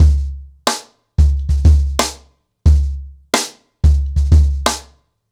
CornerBoy-90BPM.11.wav